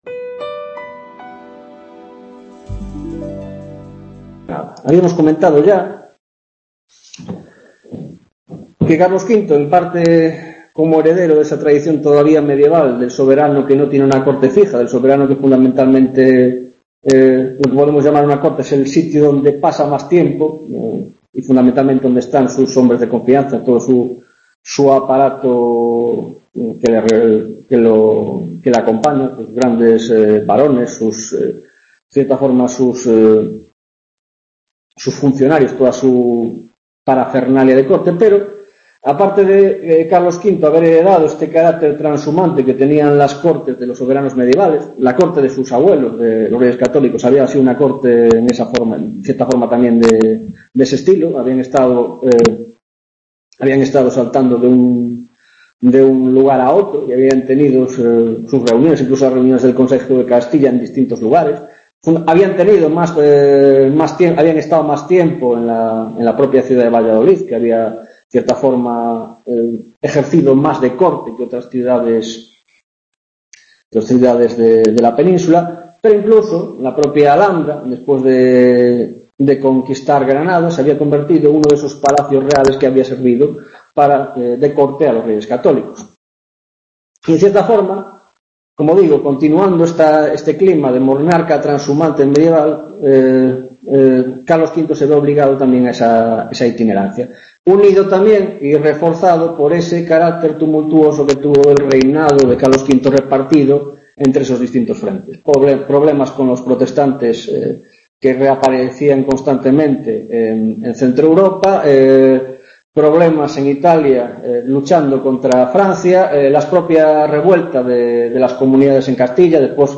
8 Tutoria de Arte y Poder en la Edad Moderna: Monarquía Hispánica, 2ª parte